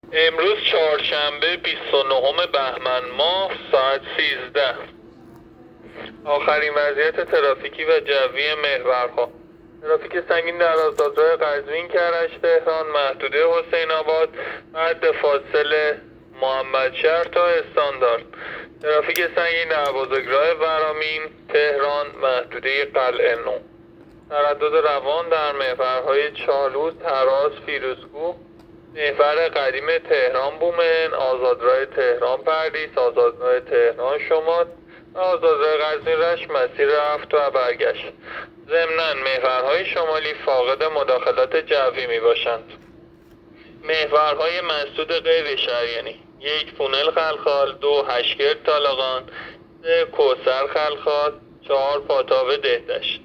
گزارش رادیو اینترنتی از آخرین وضعیت ترافیکی جاده‌ها ساعت ۱۳ بیست و نهم بهمن؛